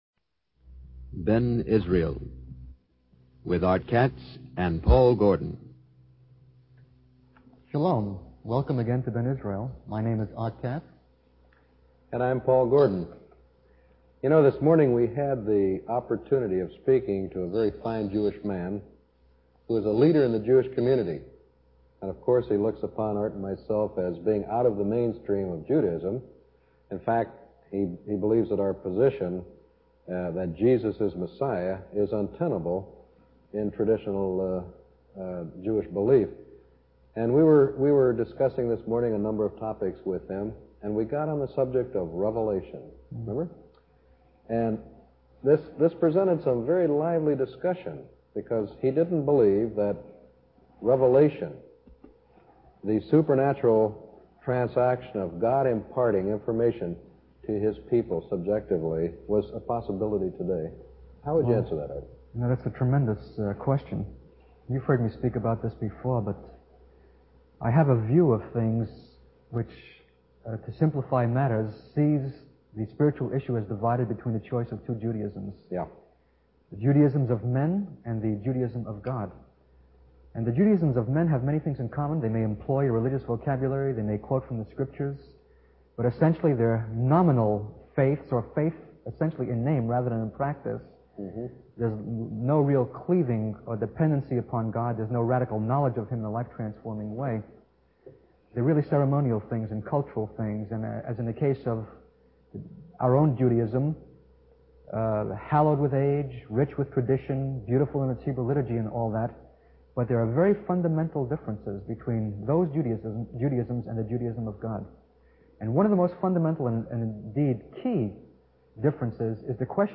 The audio messages from the Ben Israel TV show, Raleigh, NC in 1973.